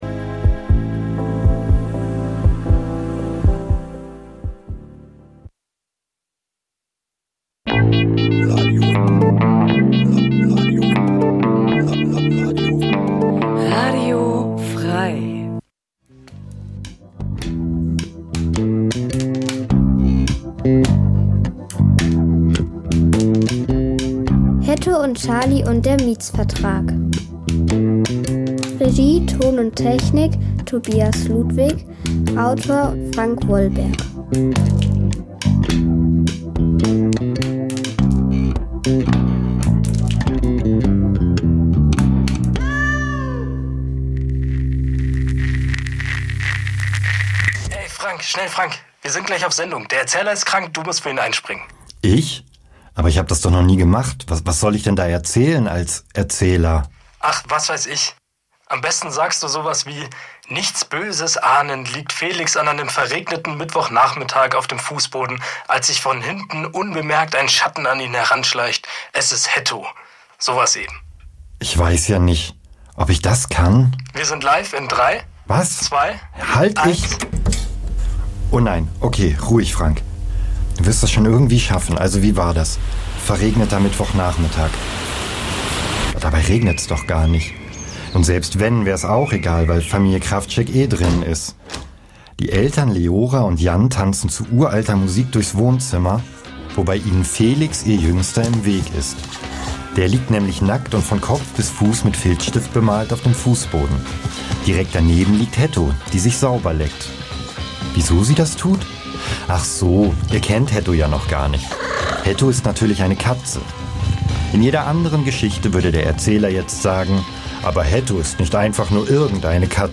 H�rspiel-Titel: Hetto und Charlie und der Miezvertrag